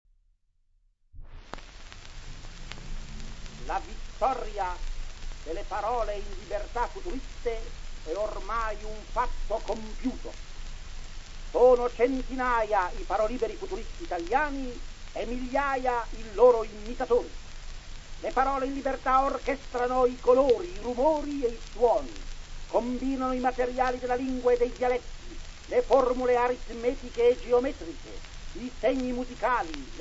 • registrazione sonora di parlato